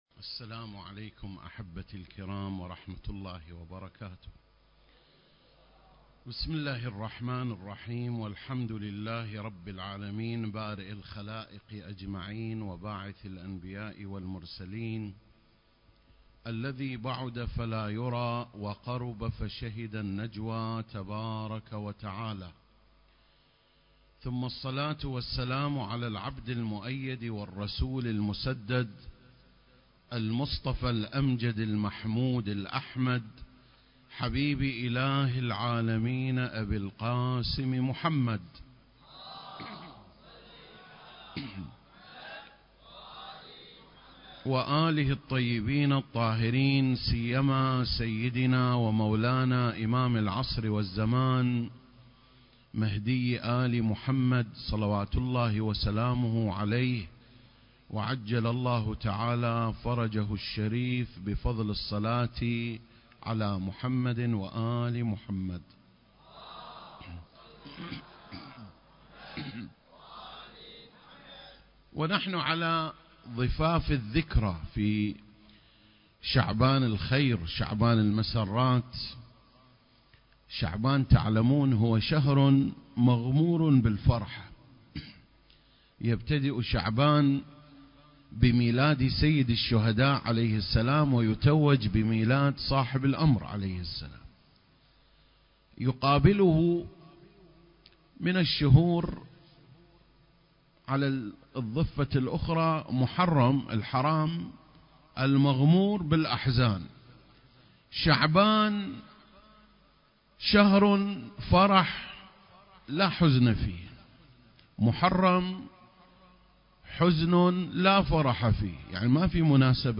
المكان: حسينية المرحوم الحاج داود العاشور/ البصرة